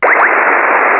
Snazim se vysvetlit, ze otazka nezni kam, ale kolikrat signal obehne zemekouli. Kdyz jsem s tim naposledy experimentoval, maximum bylo tri detekovatelne oblety - dva jasne slysitelne.